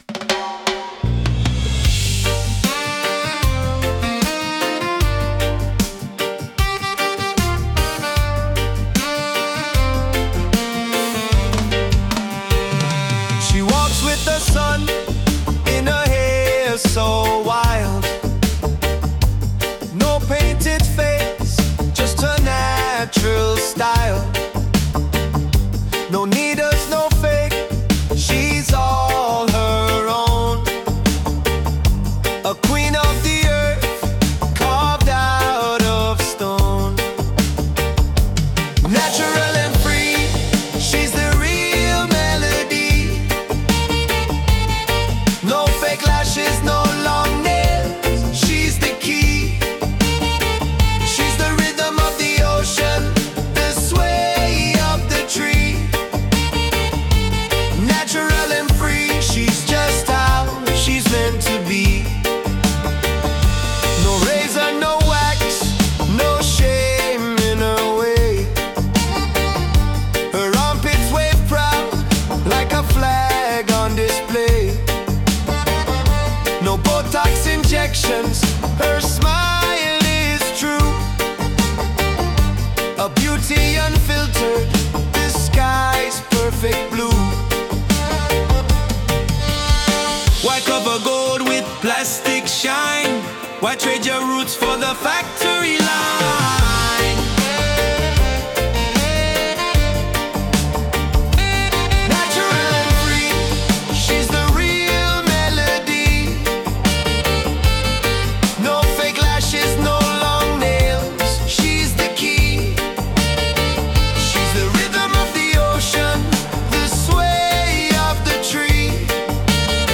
Natural-and-Free-Reggae.mp3